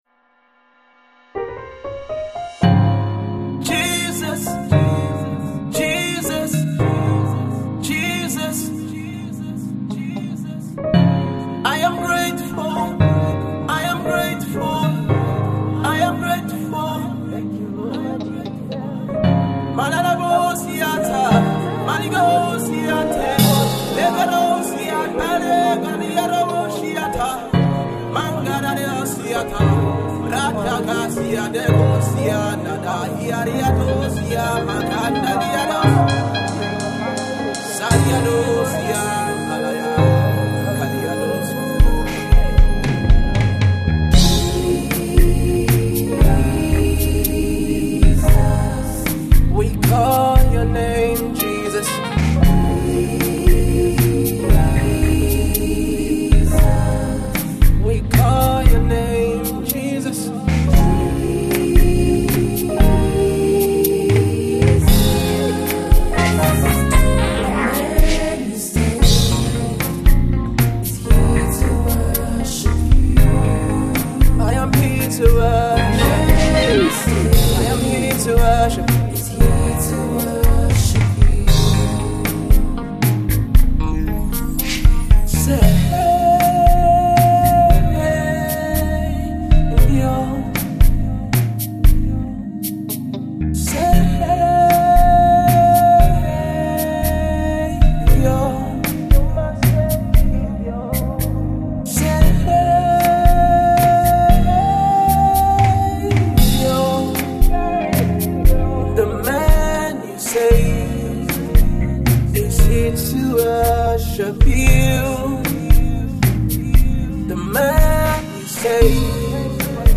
worship song